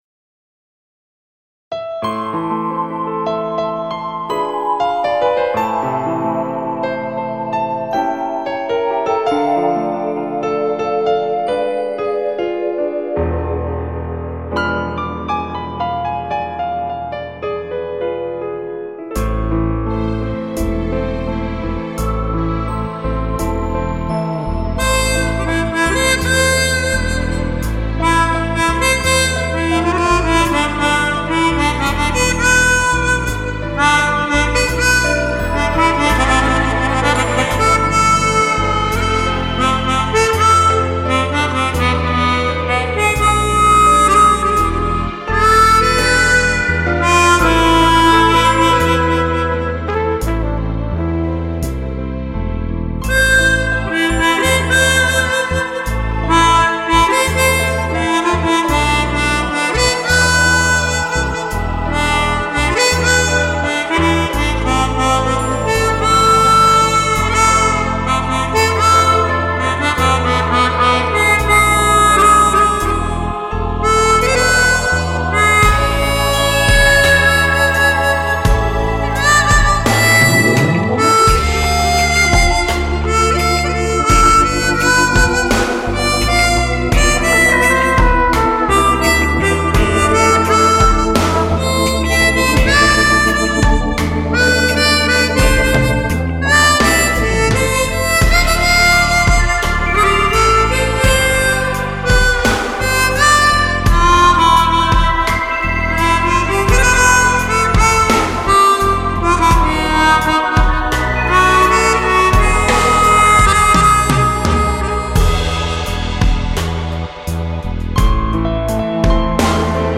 آهنگ‌های عاشقانه